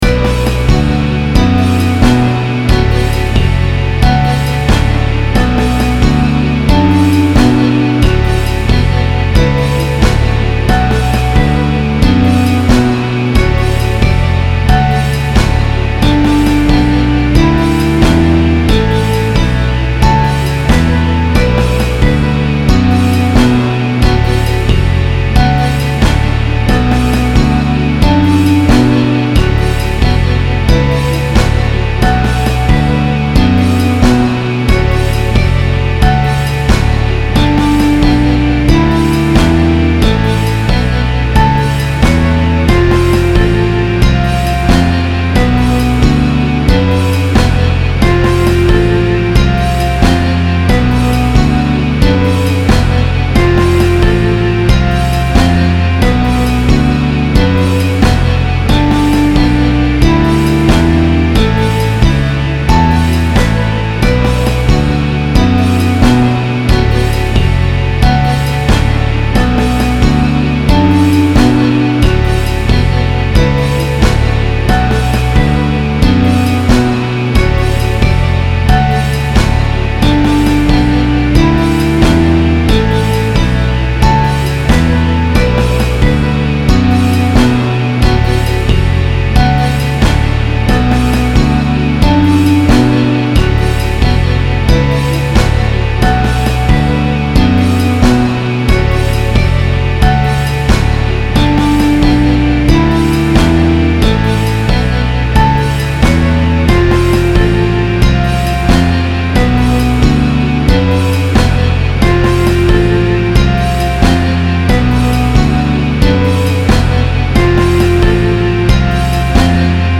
-- Basic Rhythm Section, MachFive 3 Vibrato Wurlitzer and Jazz Stratocaster with Scripted Tremolo -- MP3
I-Want-To-Dance-With-You-MF3-Wurlitzer-Jazz-Stratocaster-Tremolo.mp3